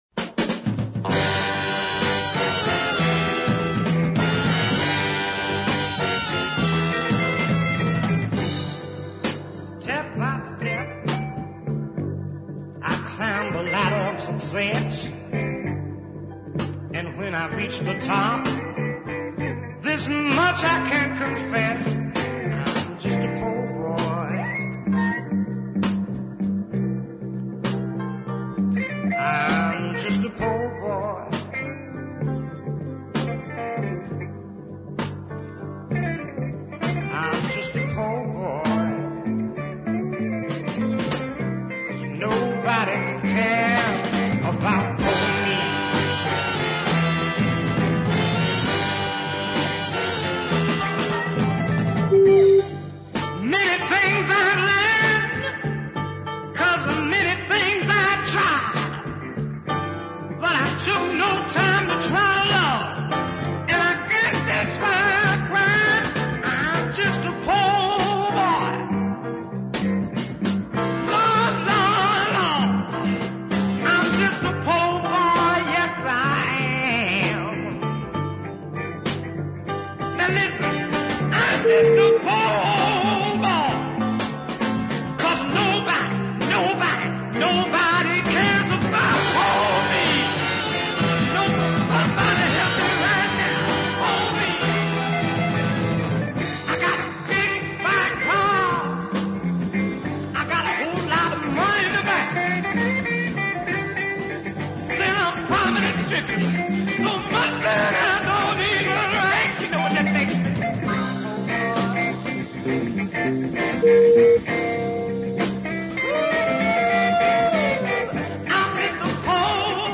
full of fire and pain.